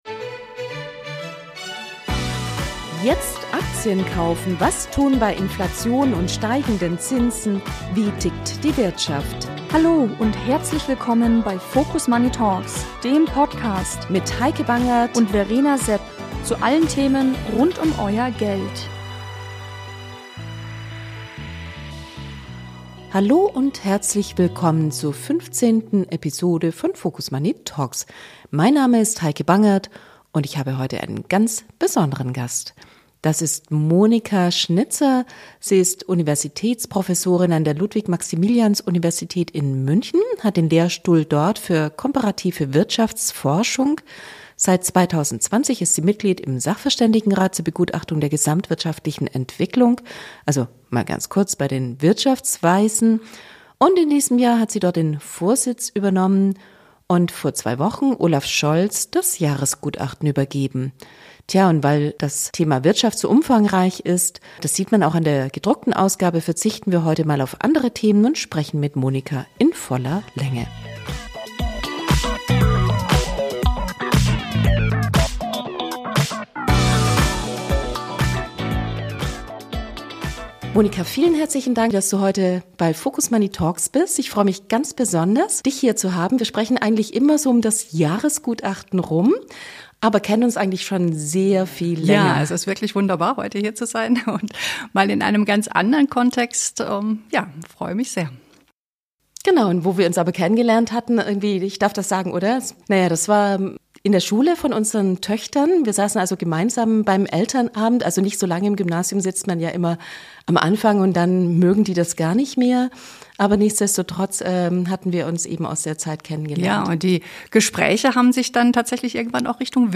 #15 Interview mit der Vorsitzenden der Wirtschaftsweisen, Monika Schnitzer, zum jüngsten Jahresgutachten ~ FOCUS MONEY Talks Podcast